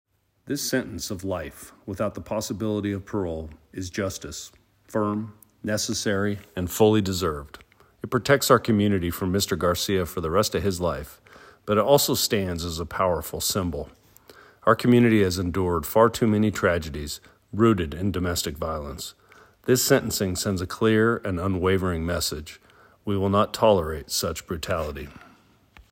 Audio from DA Hicks